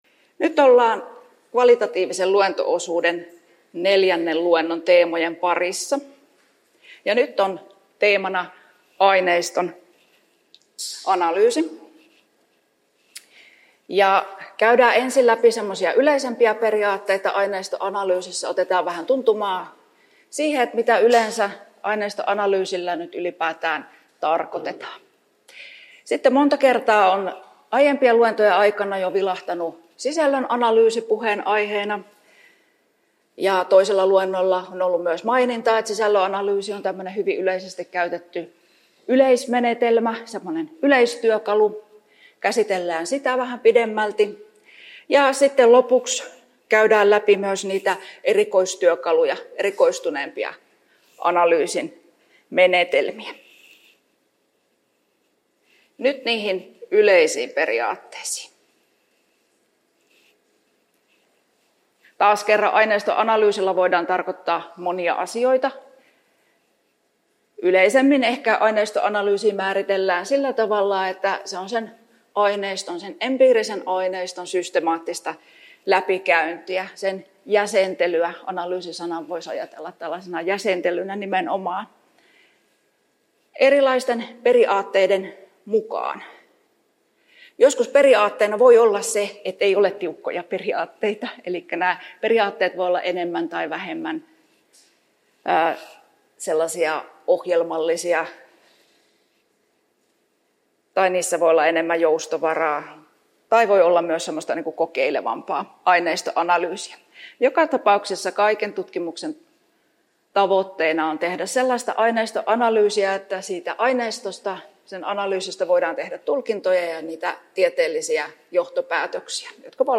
4. luento (24.9.2024) — Moniviestin